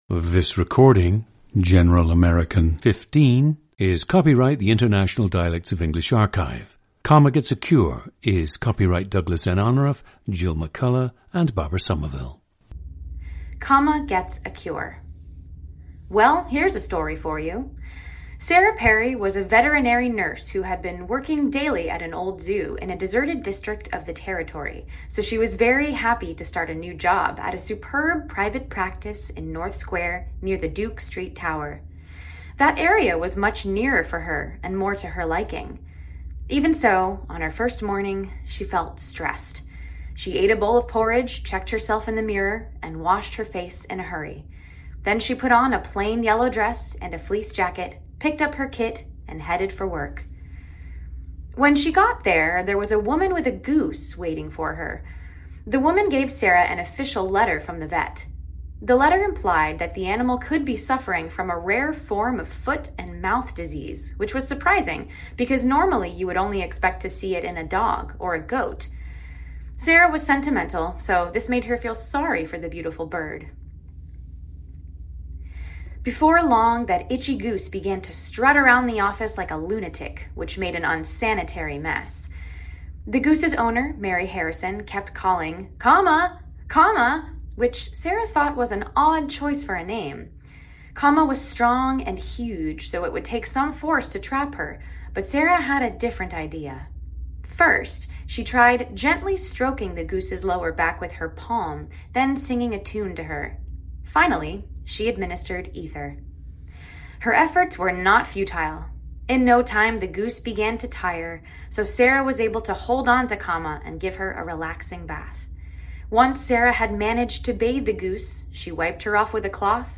General American 15